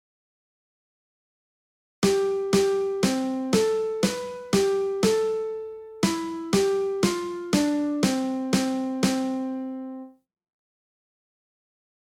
下が私の例です（スネアドラム付き）。